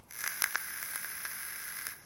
На этой странице собраны звуки электронных сигарет: шипение, бульканье, парение и другие эффекты.
Звук затяжки электронной сигареты